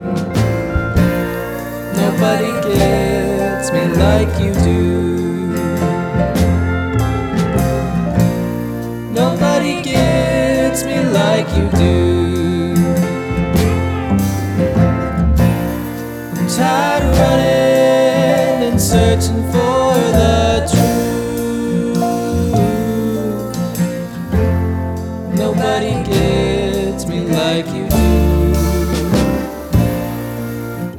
Drums
Bass
Piano
pedal steel